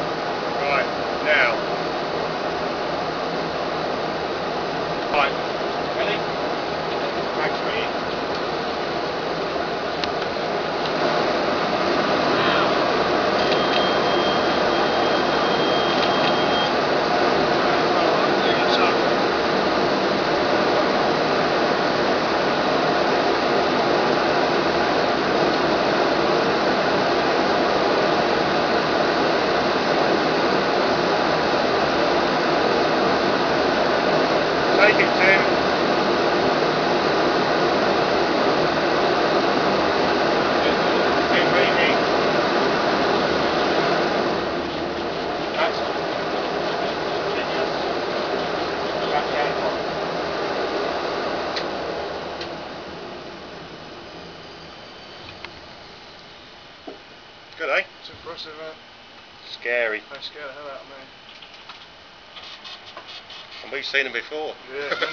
Spey 205s On Test At DRA Pyestock
Starting each engine at idle, they gradually increased the throttle as the Spey strained against the mountings of the test bed.
If you have never heard a jet engine under test before, we did manage to record some audio for you.
Spey_at_Pyestock.wav